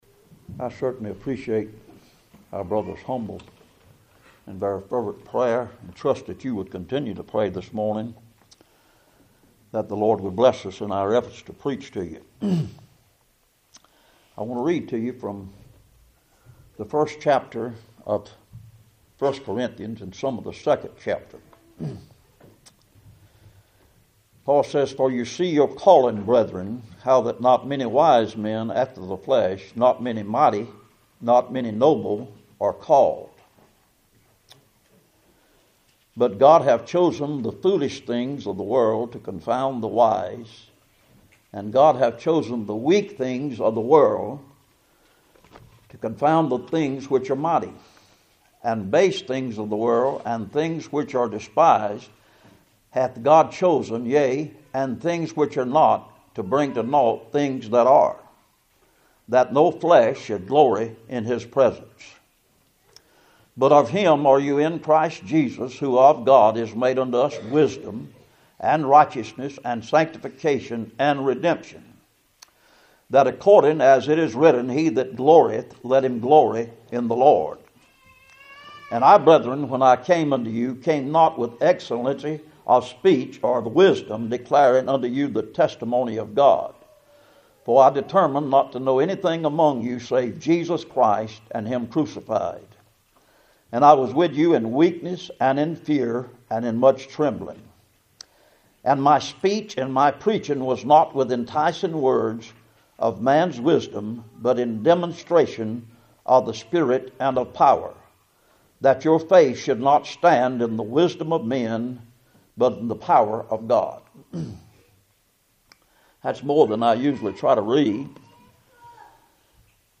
Service Type: Cool Springs PBC 1st Saturday